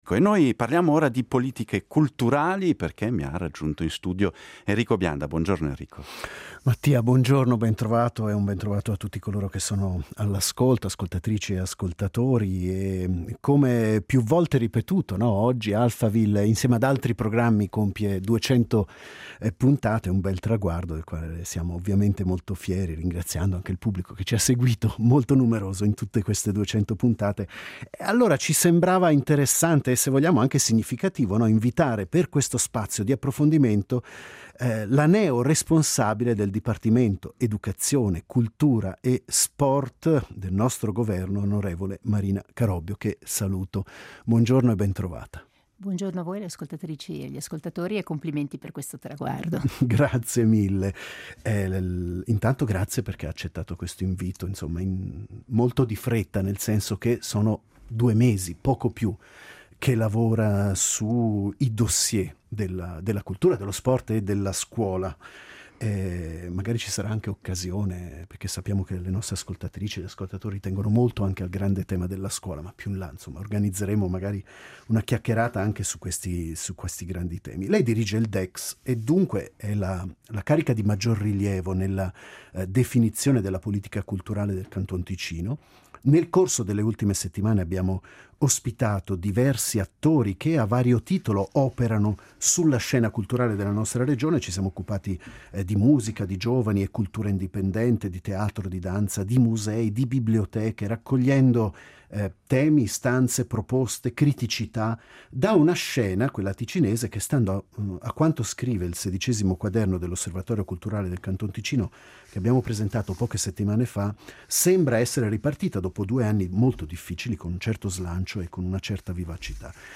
Per il nostro approfondimento ci ha raggiunti in studio un’ospite speciale: la neo-responsabile del Dipartimento educazione, cultura e sport del Canton Ticino, l’onorevole Marina Carobbio . Abbiamo cercato di capire in quale direzione intende muoversi nei prossimi anni per valorizzare il panorama culturale Ticinese.